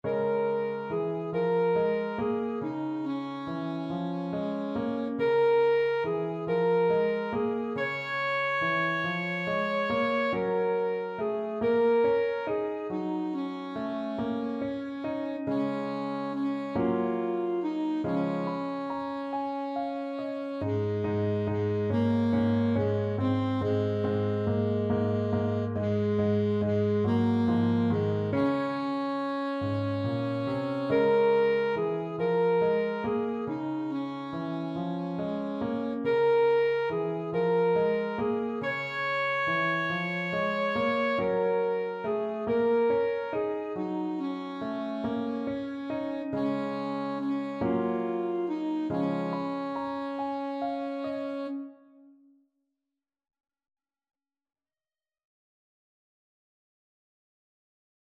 Arrangement for Alto Saxophone in Eb and Piano
3/4 (View more 3/4 Music)
= 140 Swinging
Jazz (View more Jazz Saxophone Music)